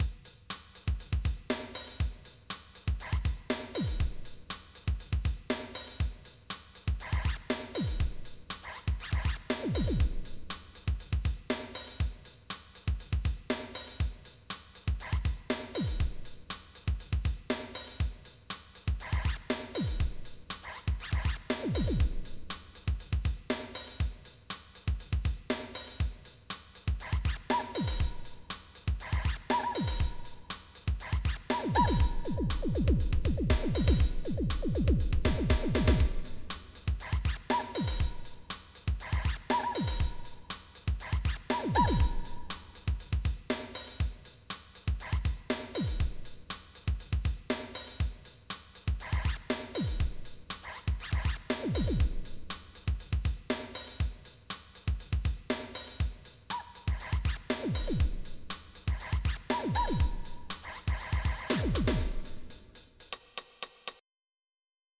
< prev MIDI Music File
5 seconds Type General MIDI